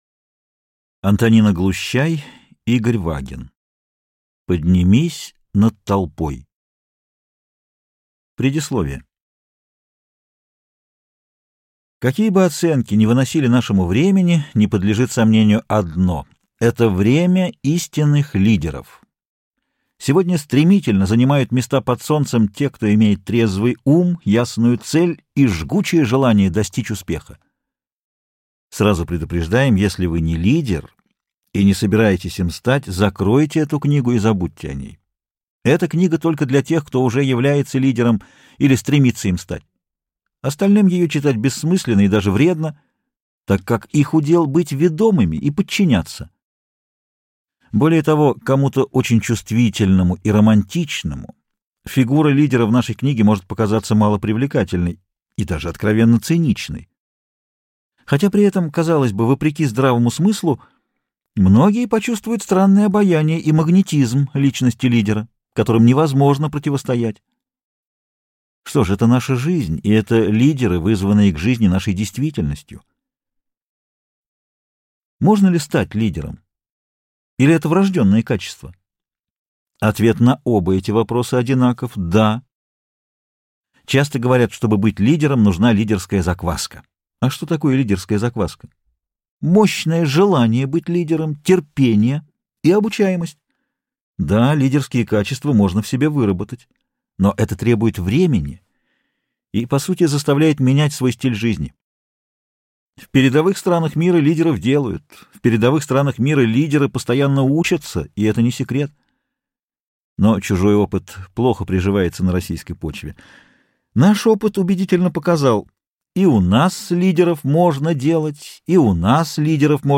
Аудиокнига Поднимись над толпой. Искусство быть лидером | Библиотека аудиокниг